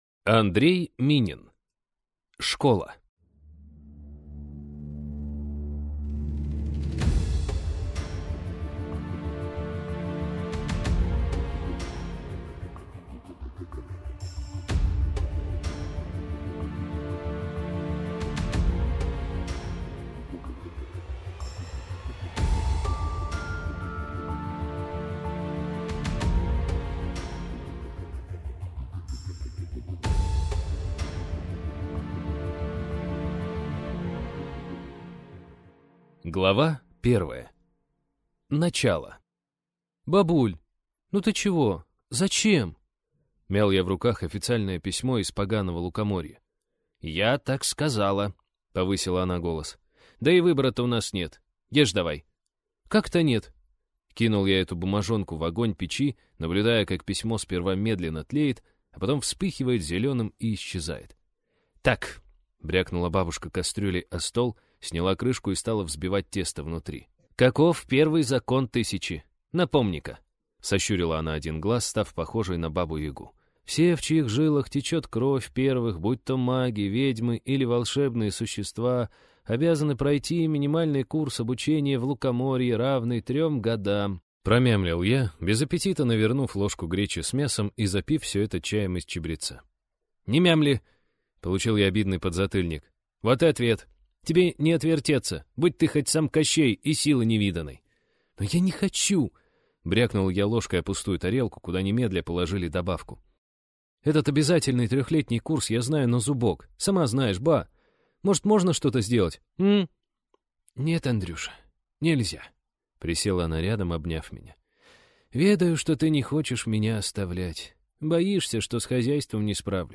Аудиокнига Школа - купить, скачать и слушать онлайн | КнигоПоиск